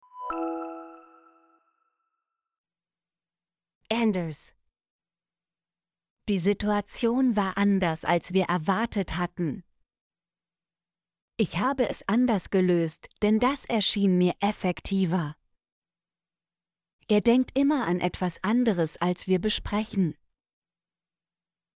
anders - Example Sentences & Pronunciation, German Frequency List